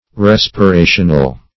Meaning of respirational. respirational synonyms, pronunciation, spelling and more from Free Dictionary.